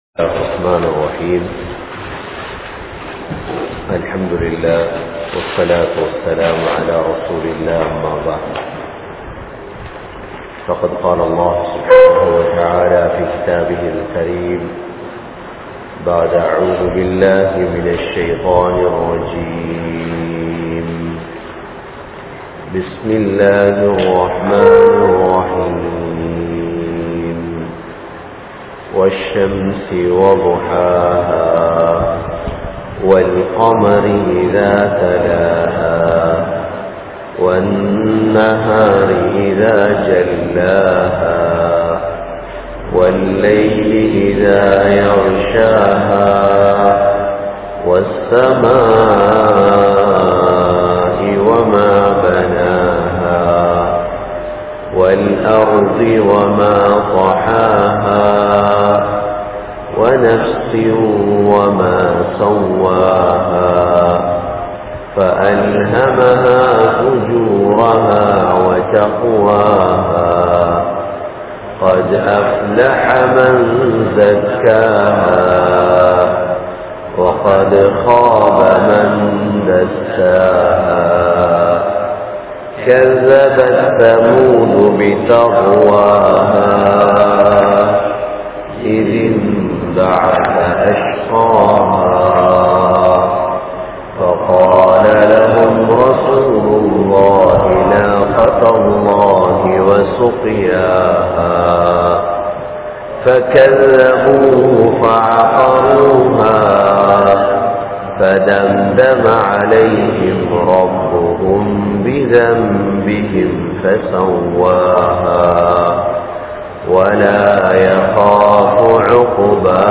Surah Shams(Thafseer) | Audio Bayans | All Ceylon Muslim Youth Community | Addalaichenai
Aluthgama, Dharga Town, Meera Masjith(Therupalli)